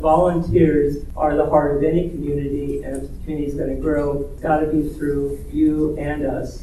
A standing-room only crowd came out Monday for a public forum on the future of Parks and Recreation programming, held at Manhattan Fire HQ.